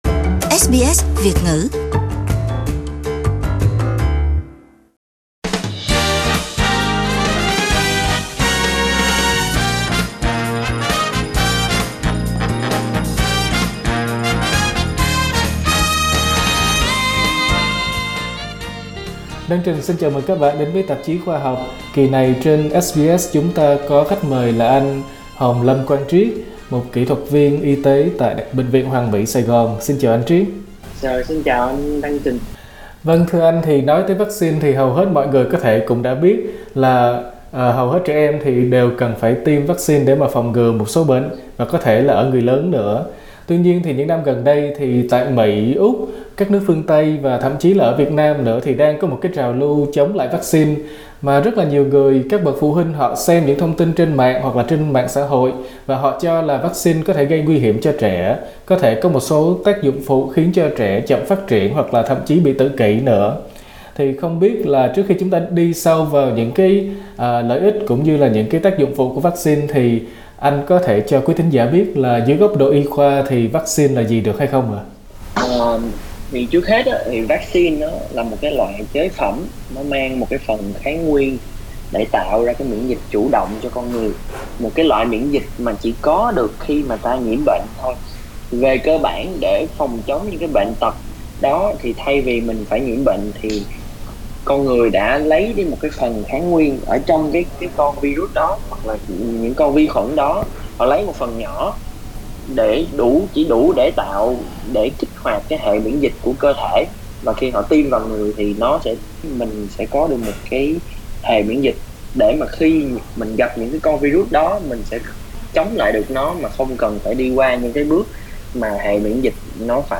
SBS Vietnamese phỏng vấn